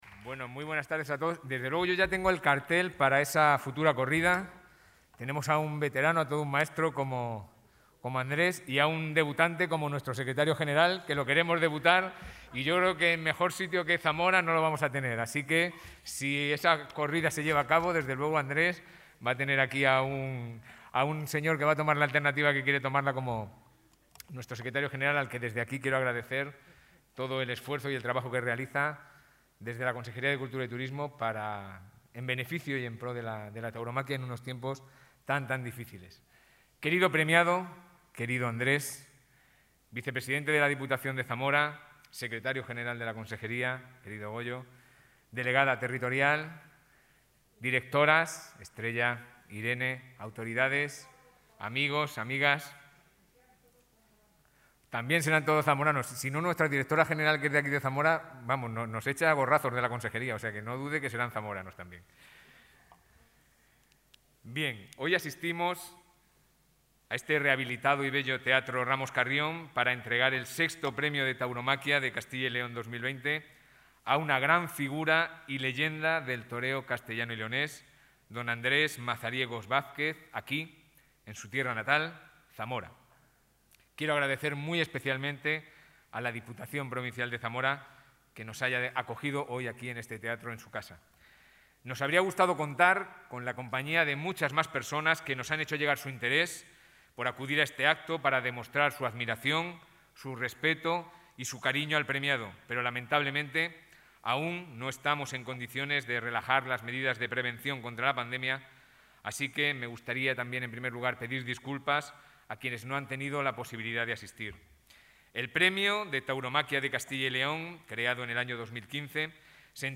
Intervención del consejero de Cultura y Turismo.
El Teatro Ramón Carrión de Zamora ha acogido hoy la entrega del VI Premio de Tauromaquia al torero Andrés Vázquez, concedido por la Junta de Castilla y León por su brillante e impecable trayectoria profesional en el mundo del toro y de la lidia.